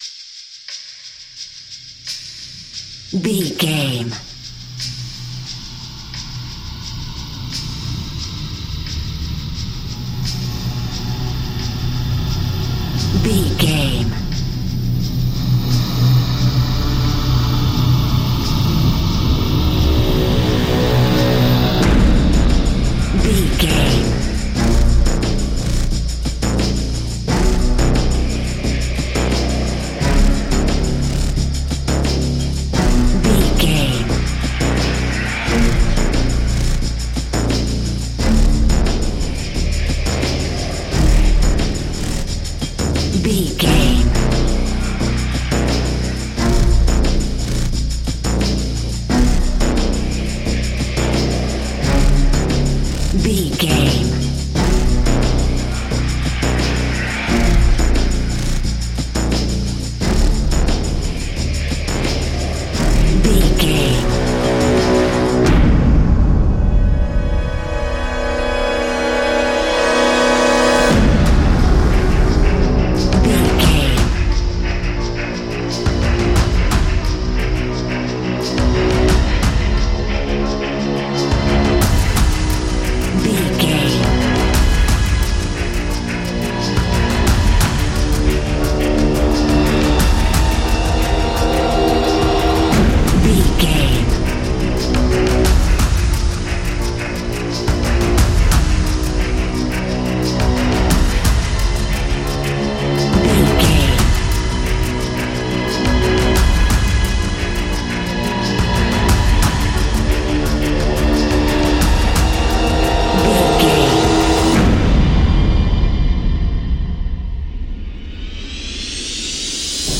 Fast paced
In-crescendo
Ionian/Major
B♭
industrial
dark ambient
EBM
experimental
synths
Krautrock